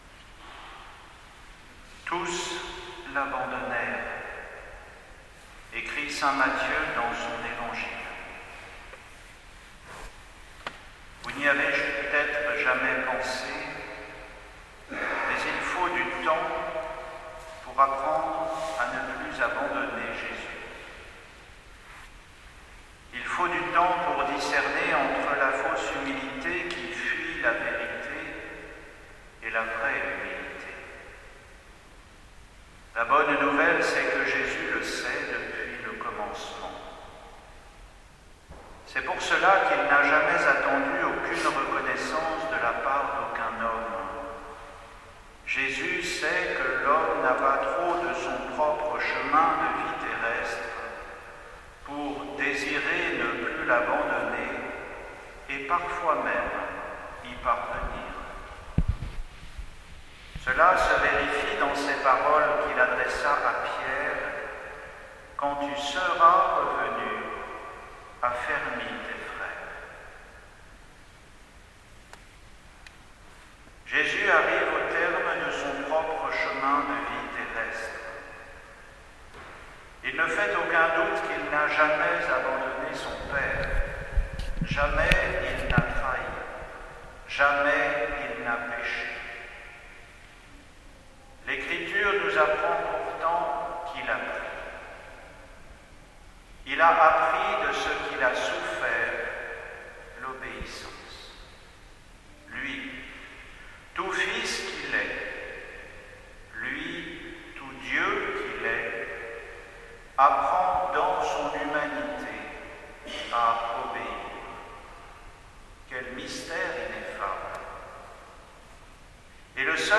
Homélie pour le Vendredi Saint
Homélie pour la célébration de la Passion, vendredi saint 2 avril 2021